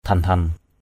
dhen-dhen.mp3